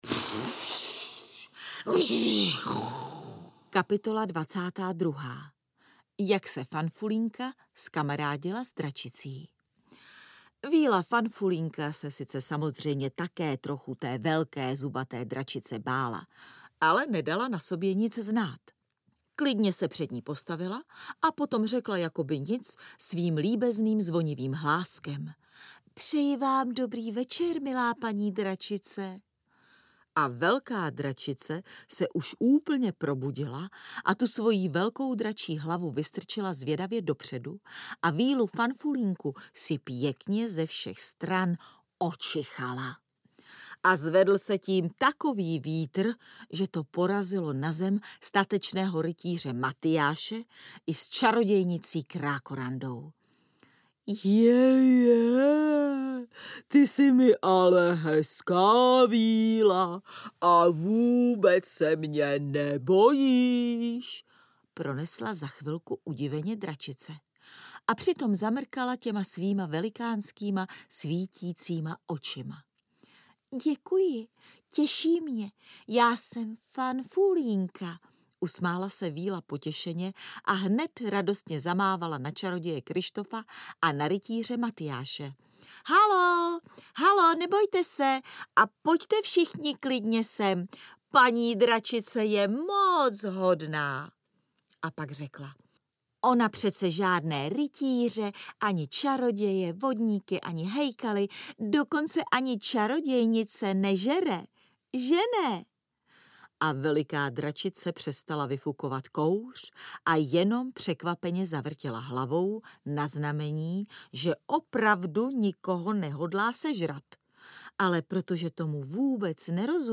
Strašidelný kongres 3 audiokniha
Ukázka z knihy
• InterpretNela Boudová